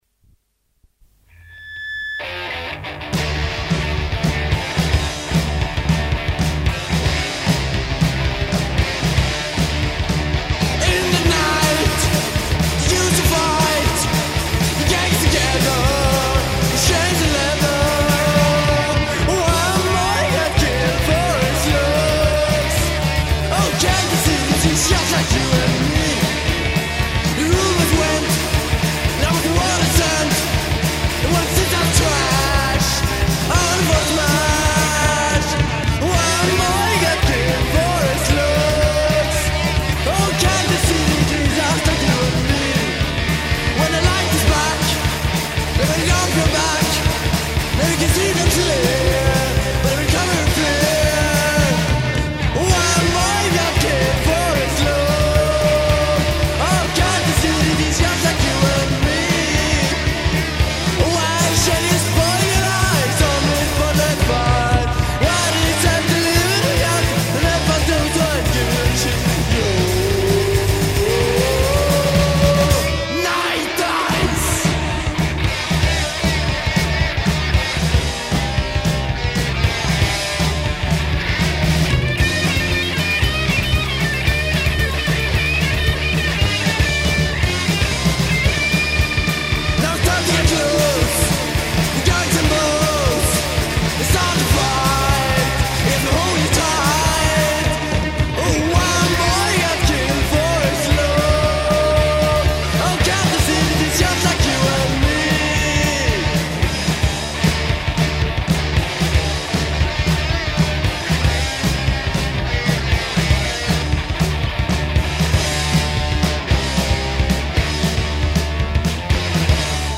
Voice, Guitar
Drums
Bass
punk band